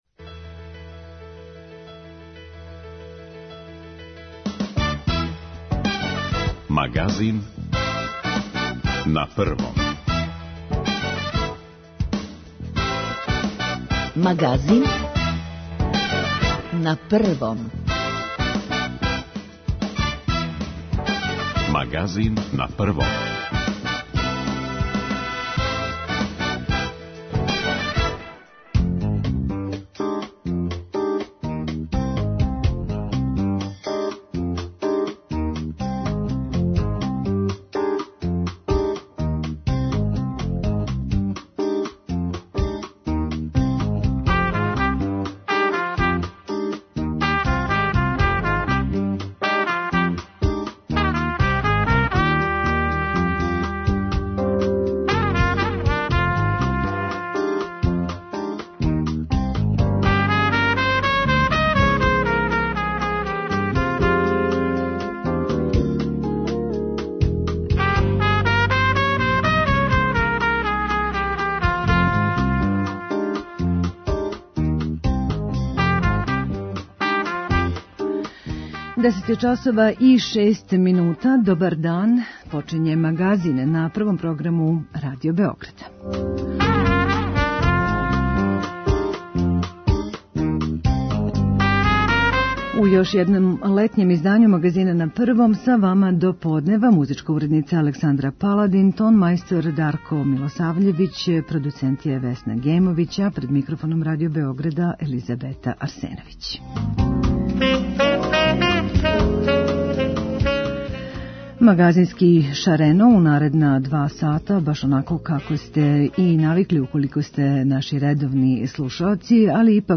Ко мозе да конкурише за даваоца услуге личног пратиоца, а ко и како за примаоца те услуге, чућемо у разговору са Наташом Станисављевић, градском секретаром за социјалну заштиту у Београду.